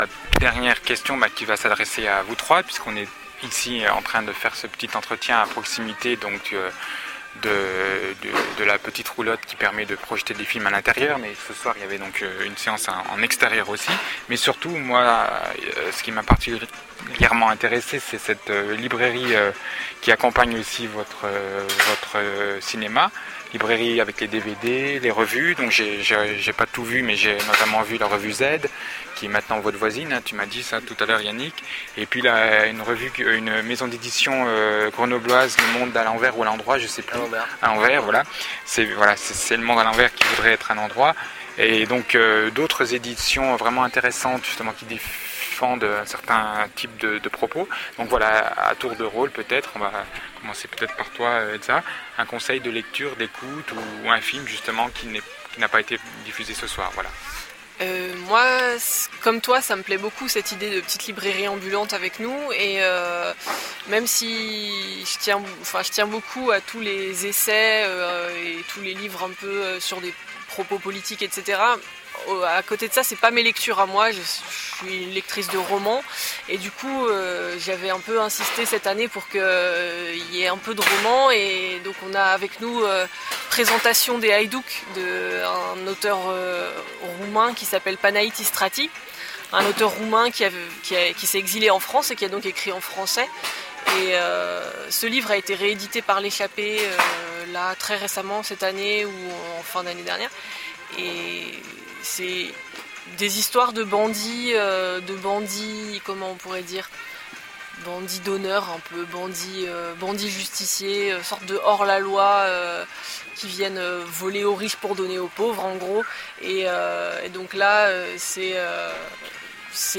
Lieu-dit : Les Rondey, Hameau de Fresse (70)